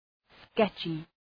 Shkrimi fonetik {‘sketʃı}